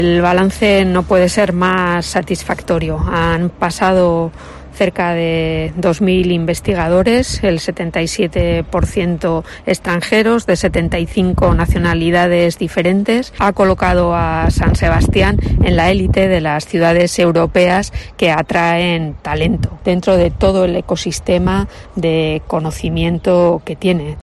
Marisol Garnendia, edil de Impulso Económico de San Sebastián